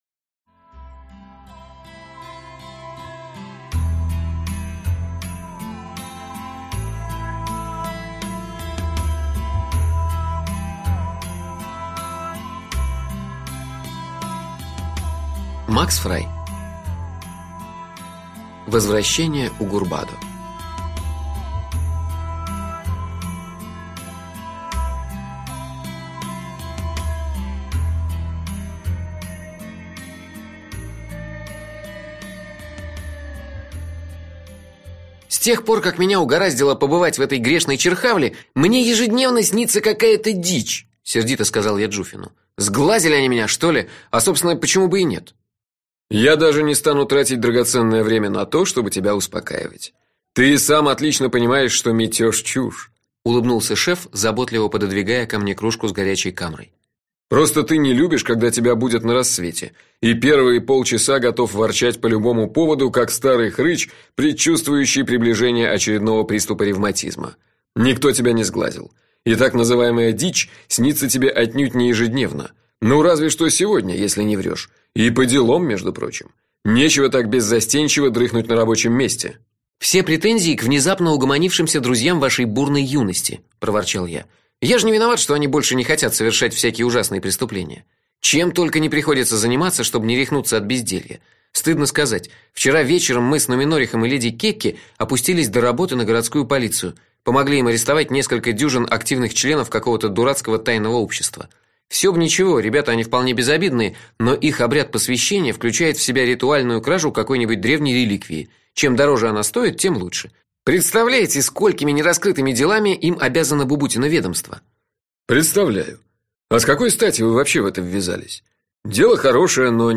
Аудиокнига Власть несбывшегося (сборник) - купить, скачать и слушать онлайн | КнигоПоиск